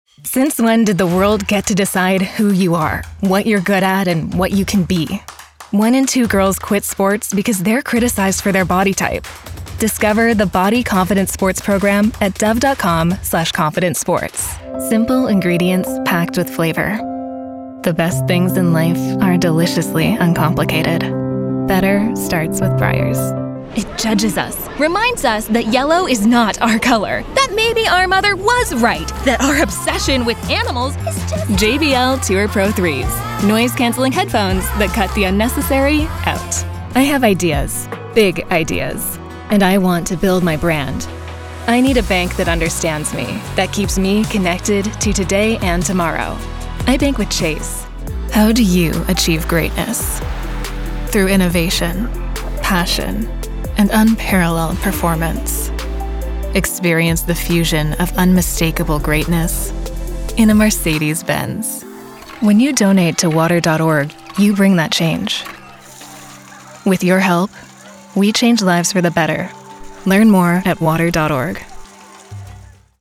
American Voice Over Talent, Artists & Actors
Adult (30-50) | Yng Adult (18-29)